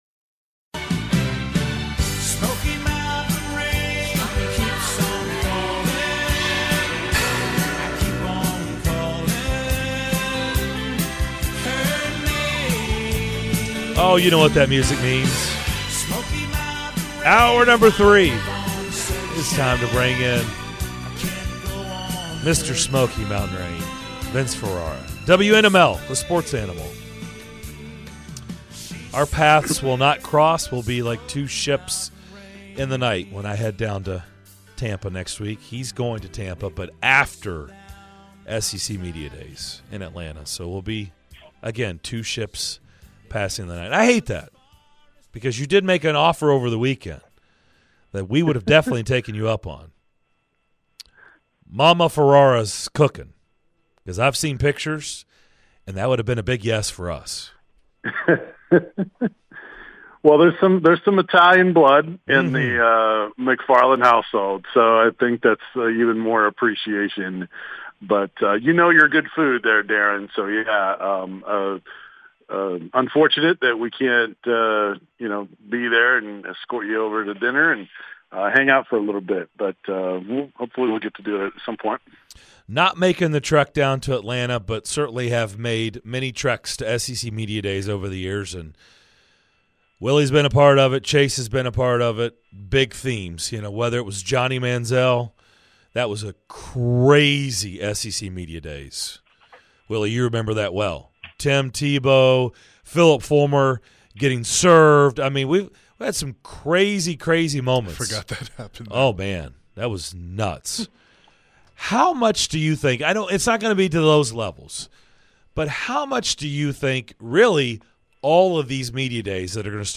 Full Interview (7-11-22)